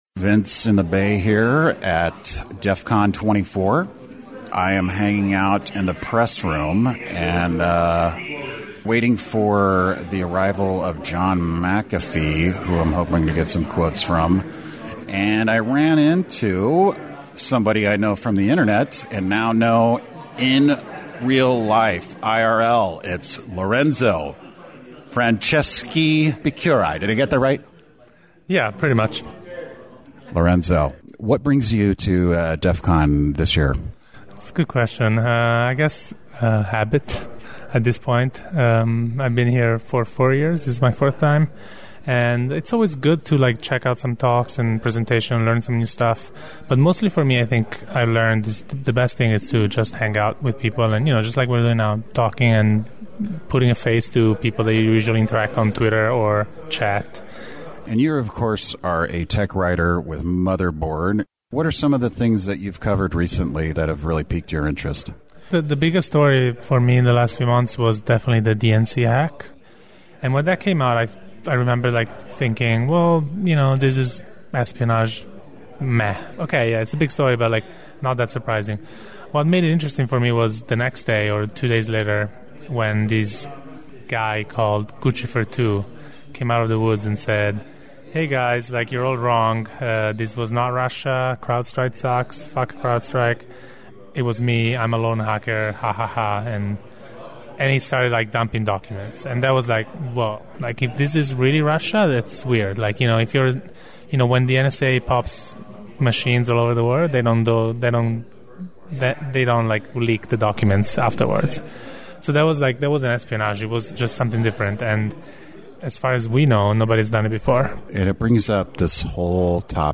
DEFCON 24 flew by way too fast, but I managed to interview several attendees.